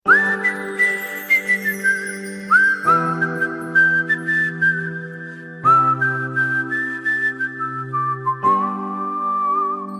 Thể loại nhạc chuông: Nhạc tin nhắn